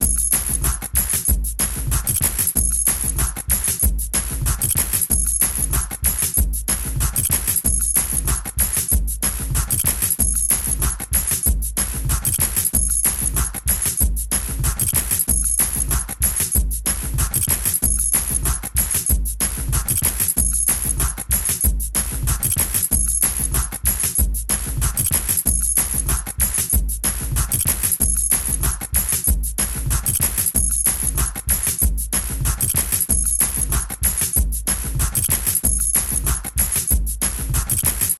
ズンジャカジャカジャカ・・・。